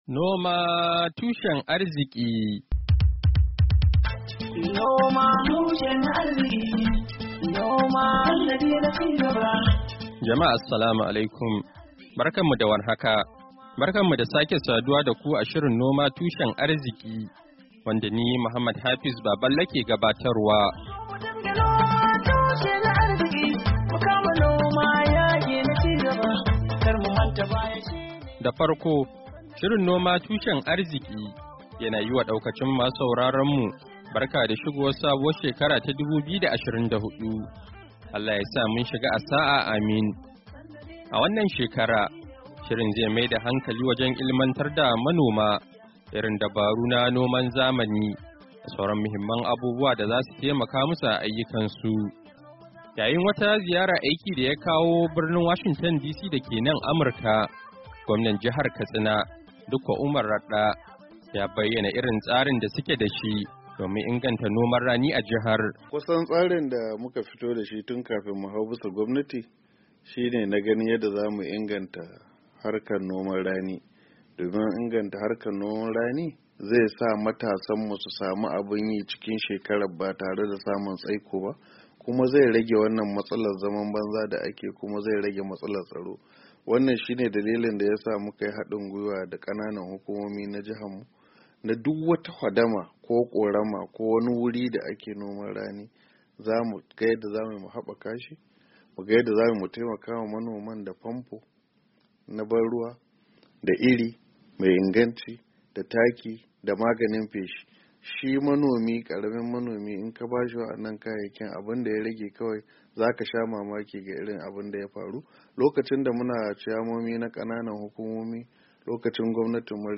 Shirin Noma Tushen Arziki na wannan makon, ya tattauna da Gwamnan jihar Katsina, Dikkon Umar Radda, kan tsari da kuma irin matakan da suke dauka, domin inganta noman rani a jihar.